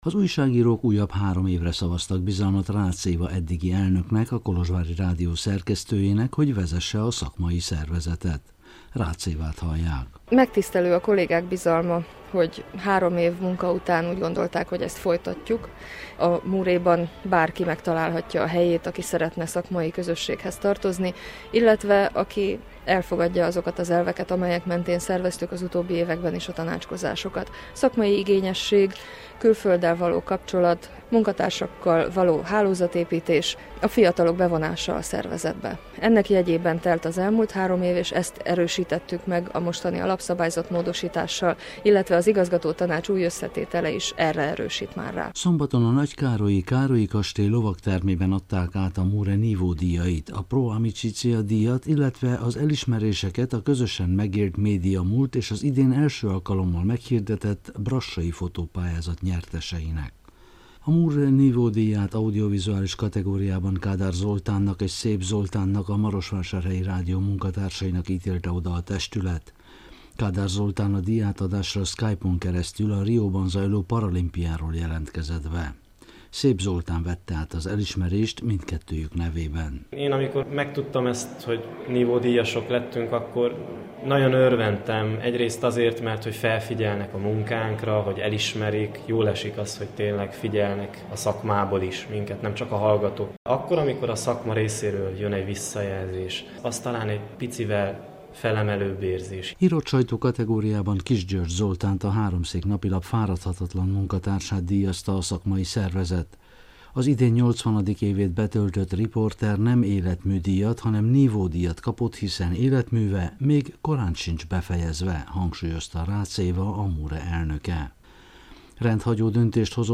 Hétvégén Nagykárolyban tartotta tisztújító közgyűlését a Magyar Újságírók Romániai Egyesülete. Ott készült következő összeállításunk.